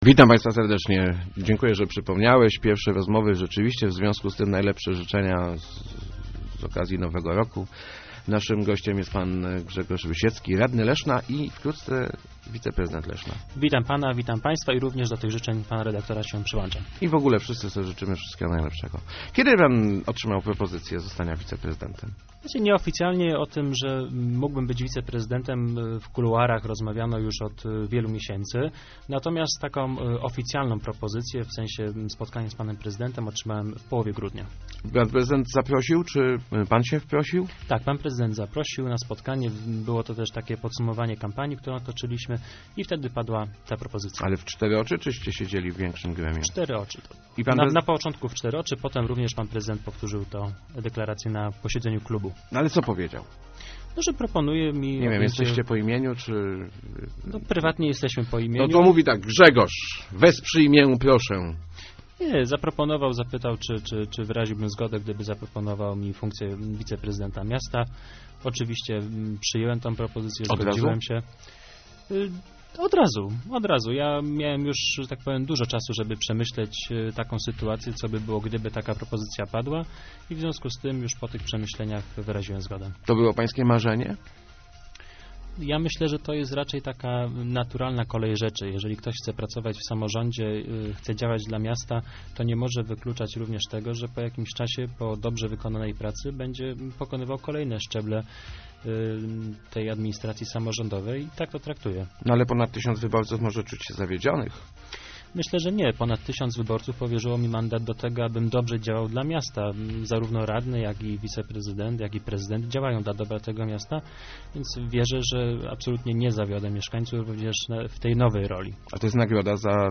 Moja praca będzie działaniem zespołowym - mówił w Rozmowach Elki Grzegorz Rusiecki, który od 1 lutego będzie wizeprezydentem Leszna. Rusiecki zakłada, że będzie współpracował zarówno z urzędnikami, jak i radnym, także tymi z Centroprawicy.